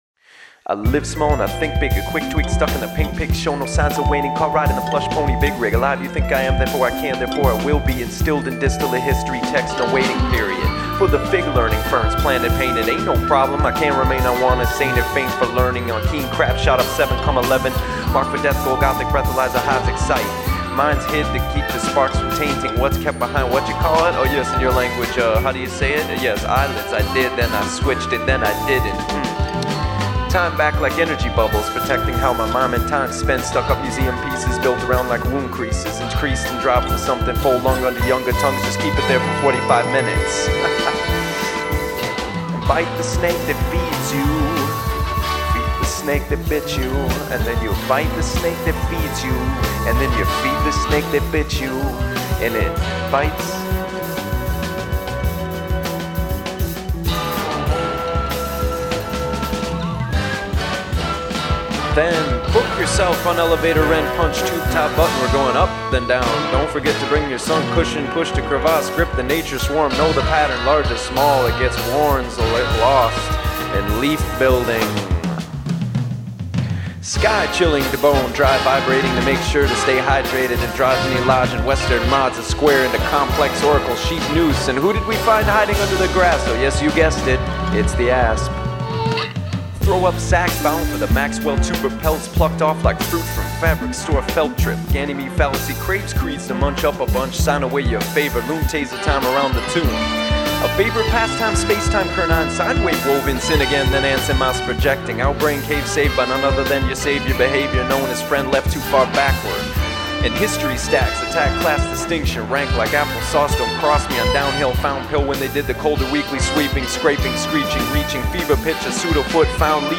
recorded in the forest  february - april 2006